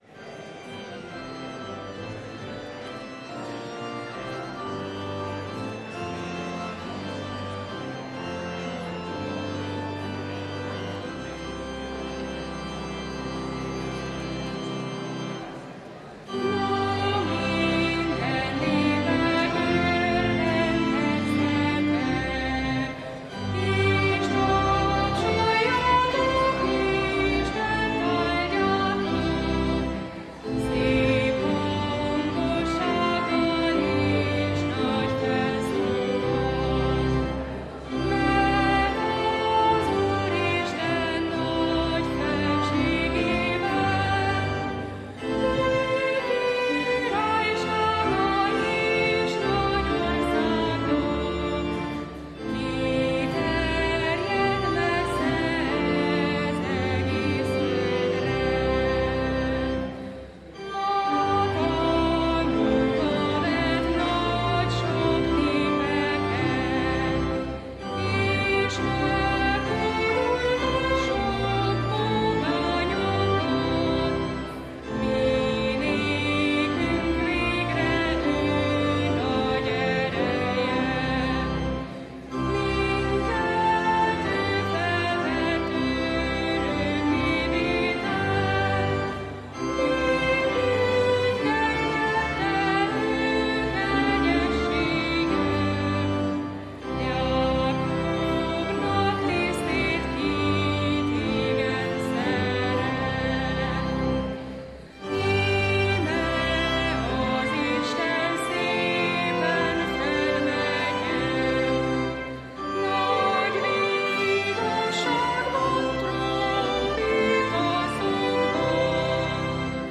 Bogárdi Szabó István 2022. május 29. hittanévzáró